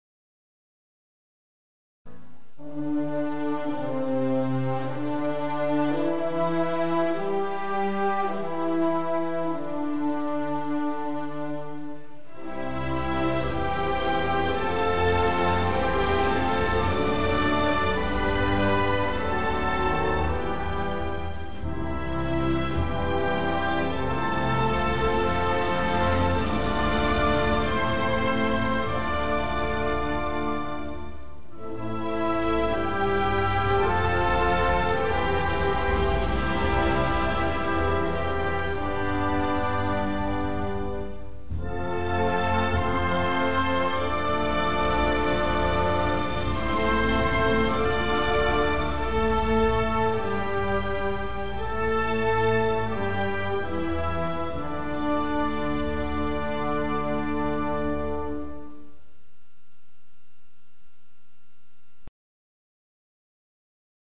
Telefone +81 Hino Nacional